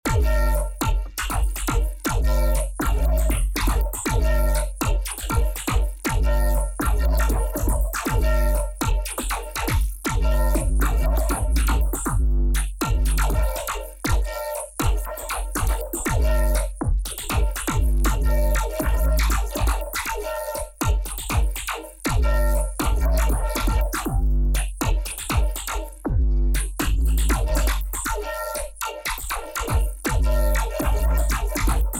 sound design samples